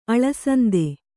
♪ aḷasande